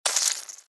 Звук укуса паука